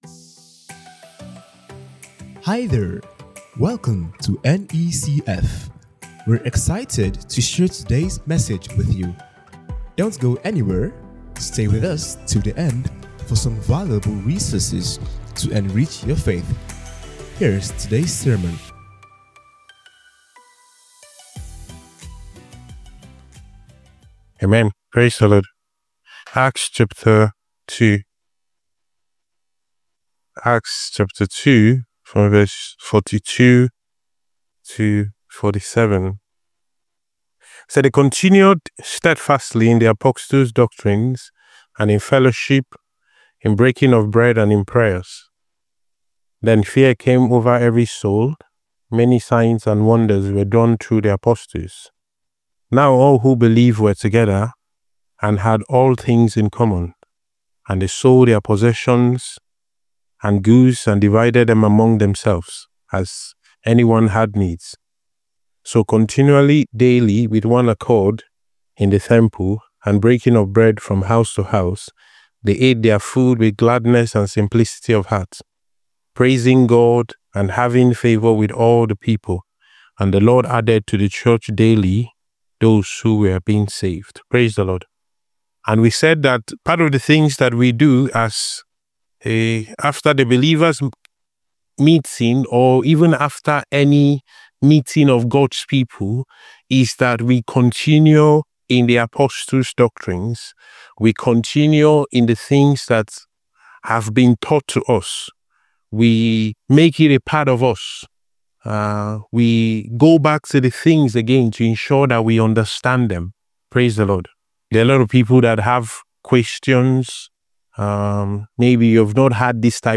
Near East Christian Fellowship Sermons Podcast - What To Do After a Believer's Conference pt. 2 | Free Listening on Podbean App